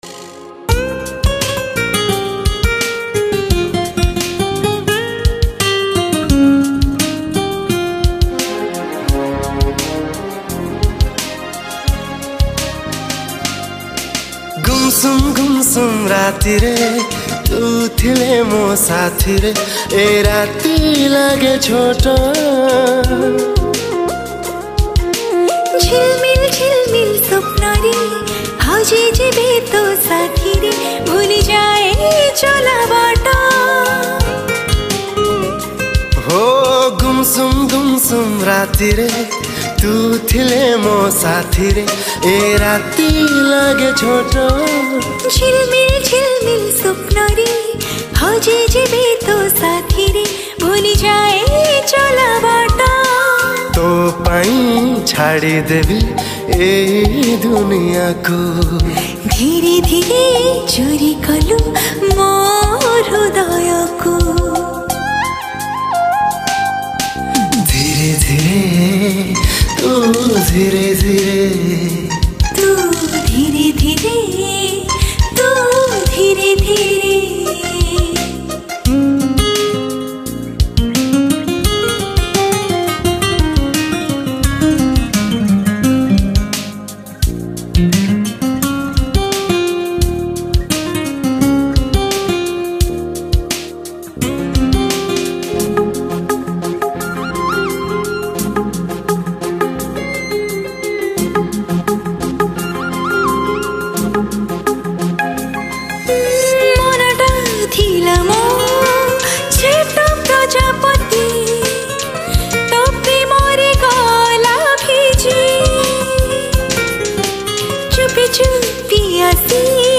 NEW ODIA ROMANTIC SONG